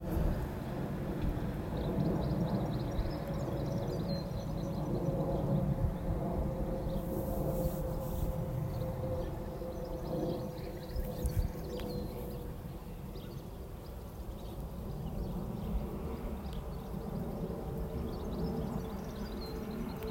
Various aircraft overfly Bothwell, though mostly Boeing 737NG aircraft, Airbus 320, and the Wespac Rescue Helicopter. Recently, I had made a 20 second recording of a Jetstar A320-232 jet flying overhead about 10,000 feet. When I played it back, I could hearing birds chirping.